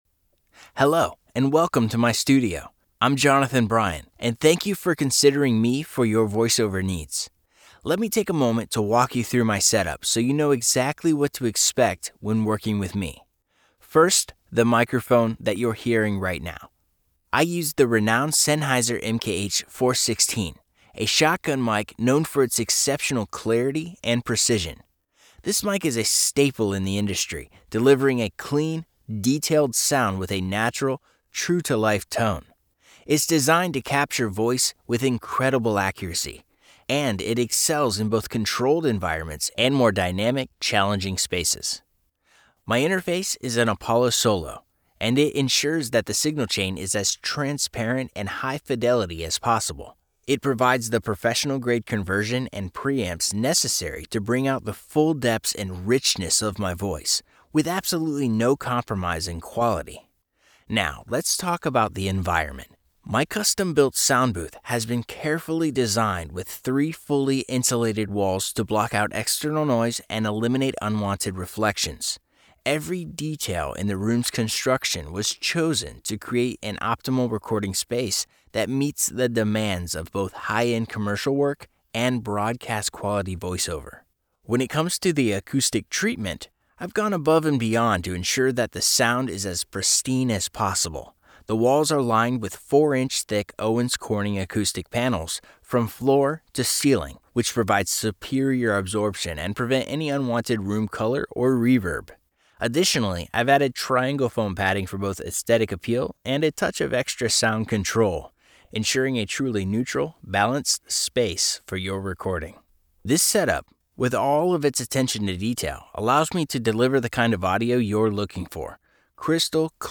Male
My voice is warm, grounded, and naturally reassuring, with a smooth American tone that feels trustworthy the moment it hits the ear.
Studio Quality Sample
Unprocessed VO Tone
1201Raw_Sample-2.mp3